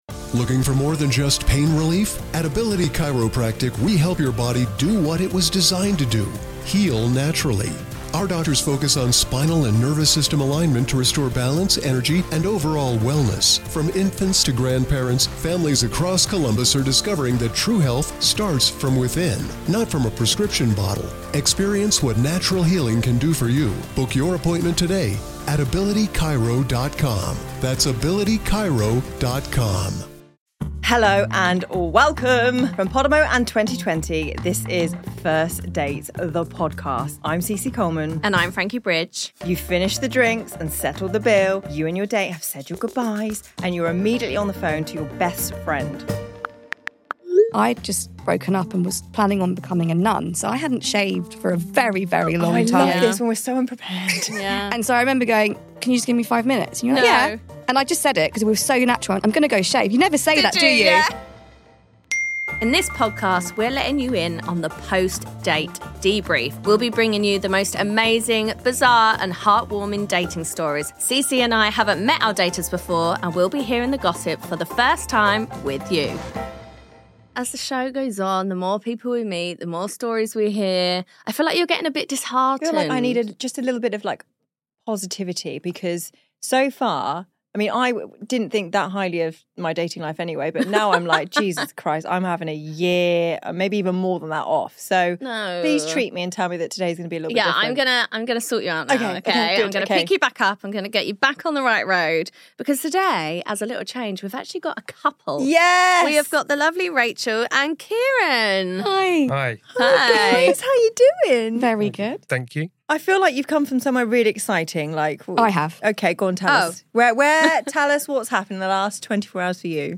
For today's loved-up episode, we've got a couple.